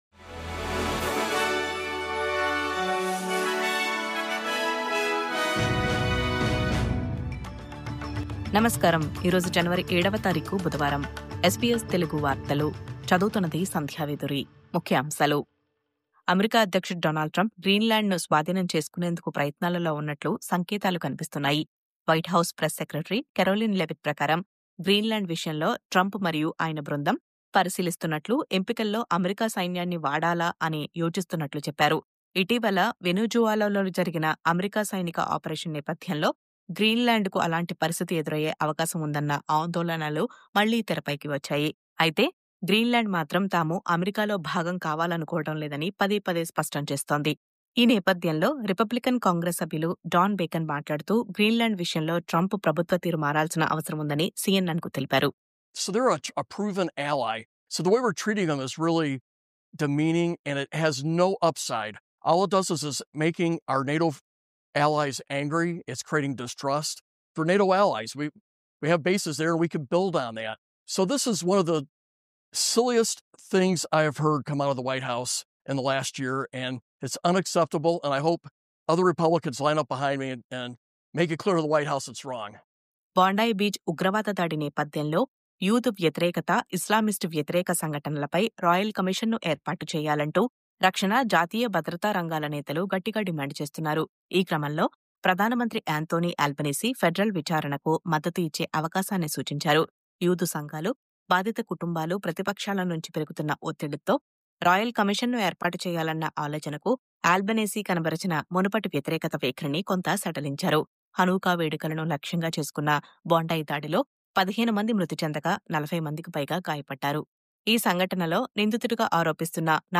News update: గ్రీన్‌ల్యాండ్‌ను స్వాధీనం చేసుకునే యోచనలో అమెరికా..